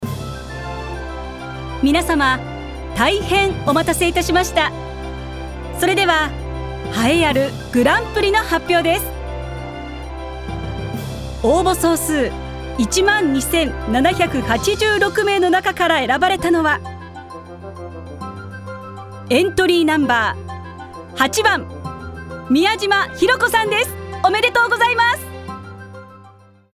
声を聴く Voice Sample
4.セレモニー司会